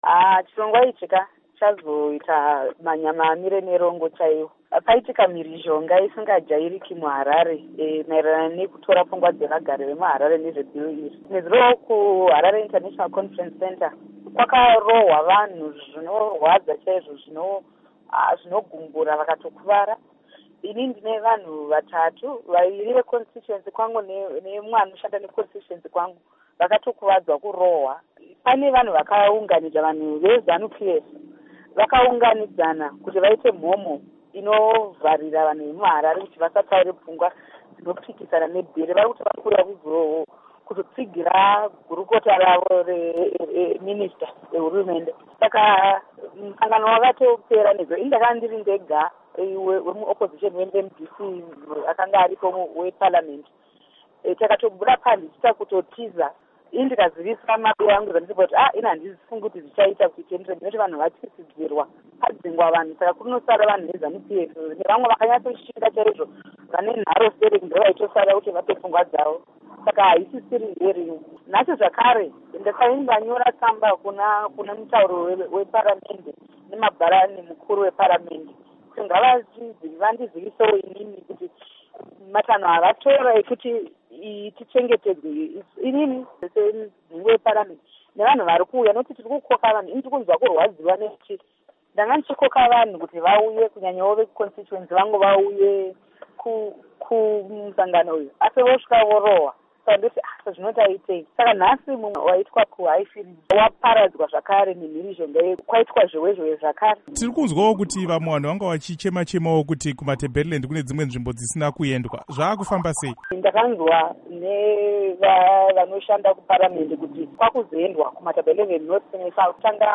Hurukuro naMuzvare Jessie Majome